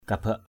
/ka-baʔ/